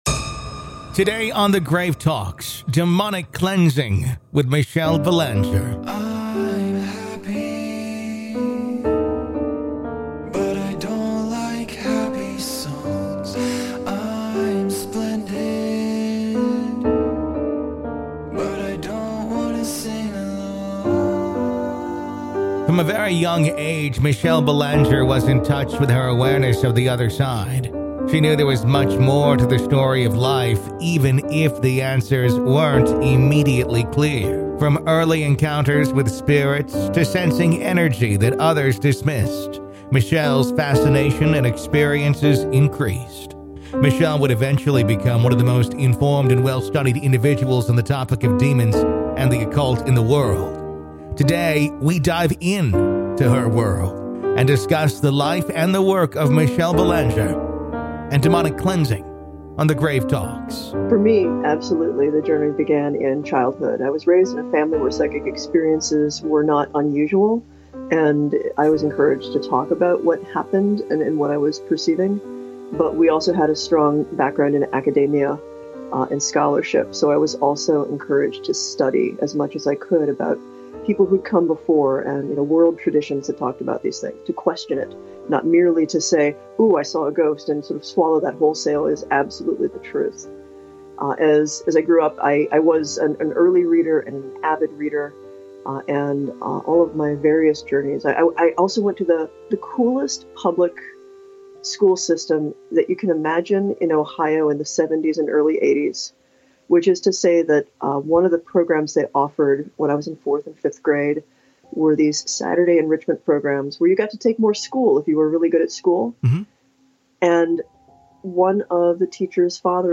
Michelle Belanger Interview | Grave Talks Classic